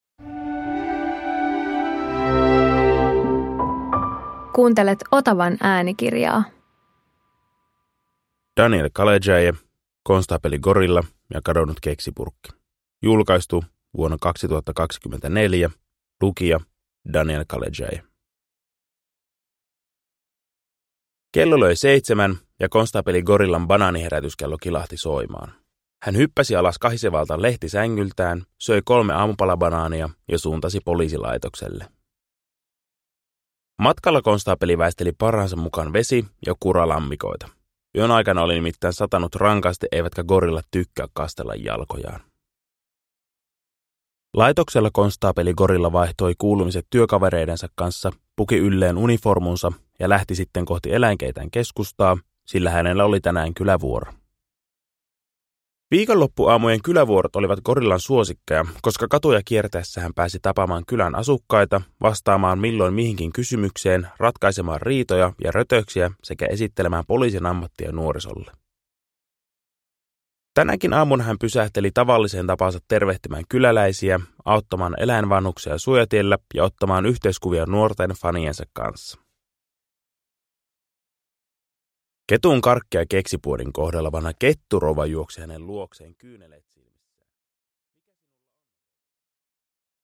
Konstaapeli Gorilla ja kadonnut keksipurkki – Ljudbok